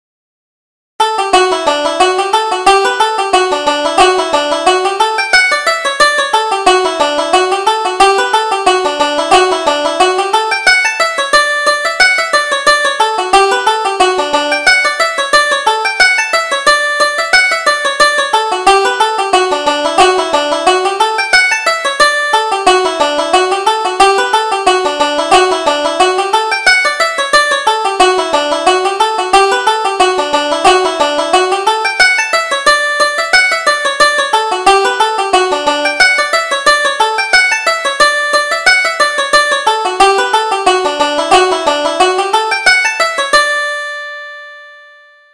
Reel: The Plaid Mantle